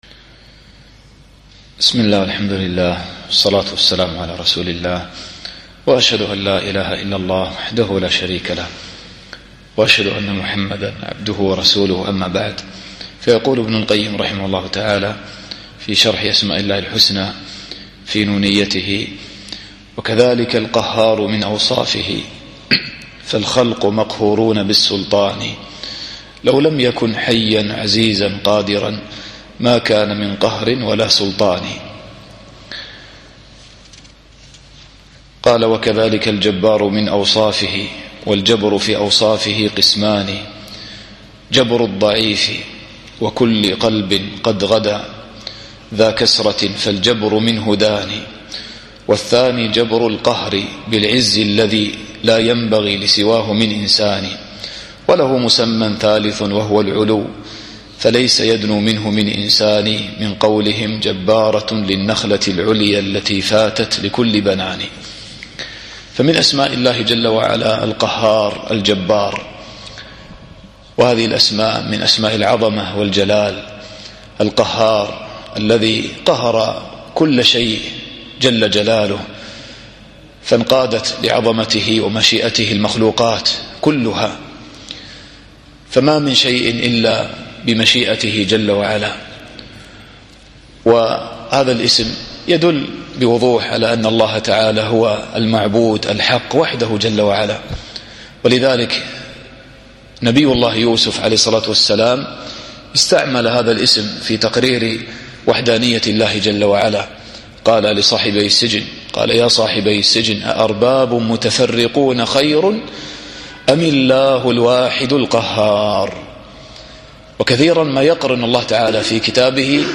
الدرس الرابع والثلاثون